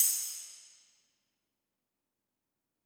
PERC - GLASS.wav